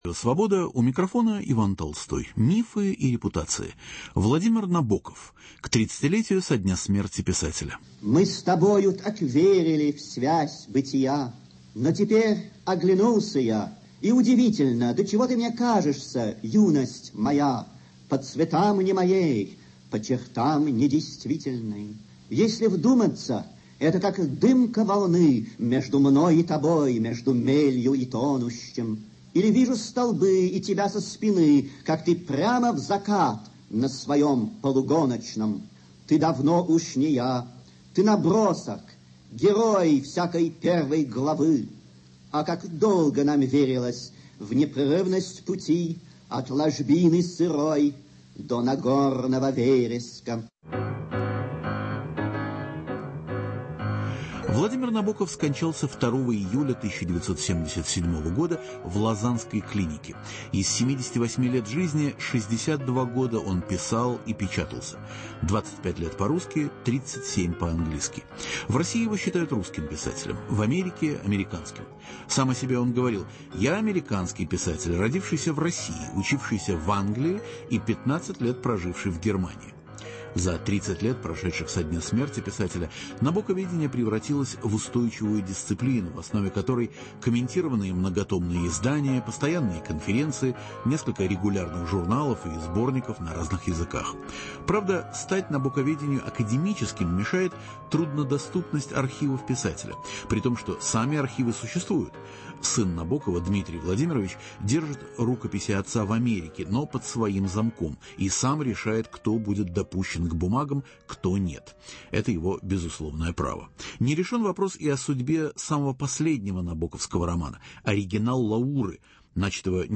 Прозвучат стихи в набоковском чтении.